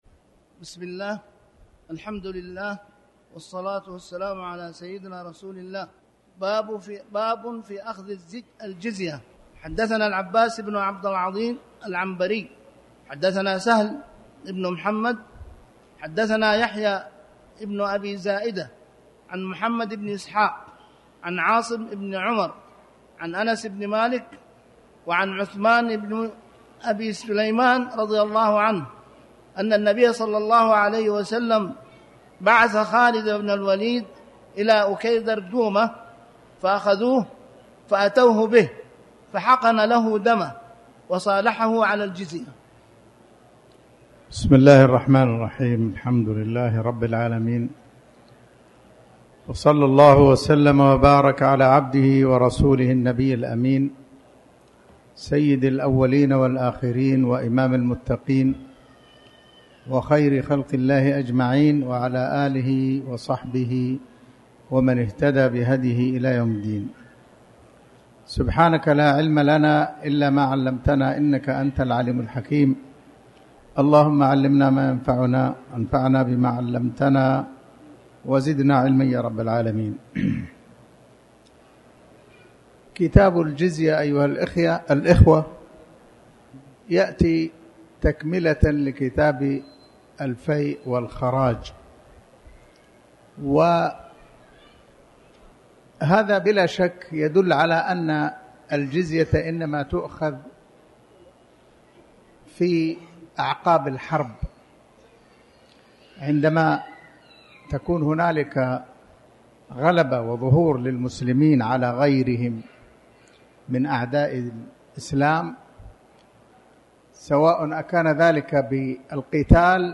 تاريخ النشر ٢٣ شوال ١٤٣٩ هـ المكان: المسجد الحرام الشيخ